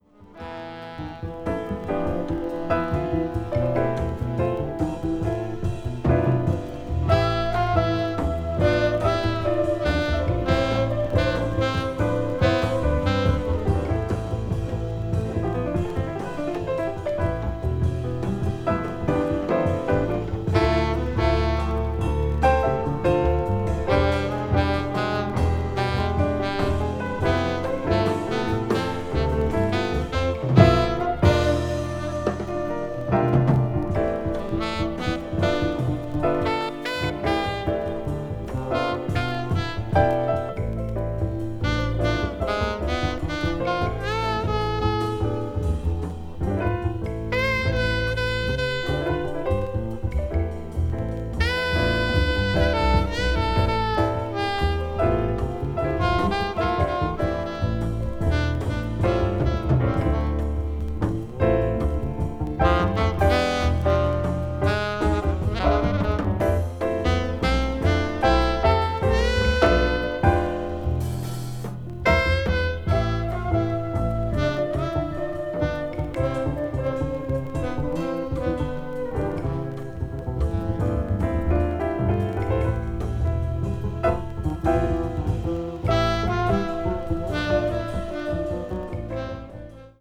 media : EX+/EX(わずかにチリノイズが入る箇所あり)